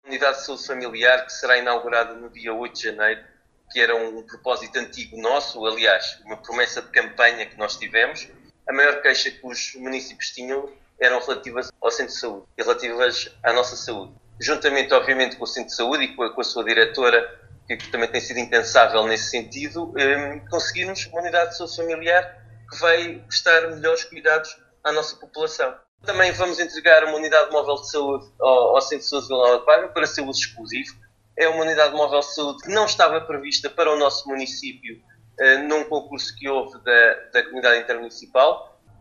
Paulo Marques, Presidente do Município de Vila Nova de Paiva, em declarações à Alive Fm, fala desta inauguração “esta Unidade de Saúde Familiar vai prestar melhores cuidados à nossa população…”.